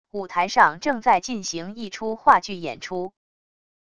舞台上正在进行一出话剧演出wav音频